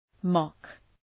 Shkrimi fonetik {mɒk}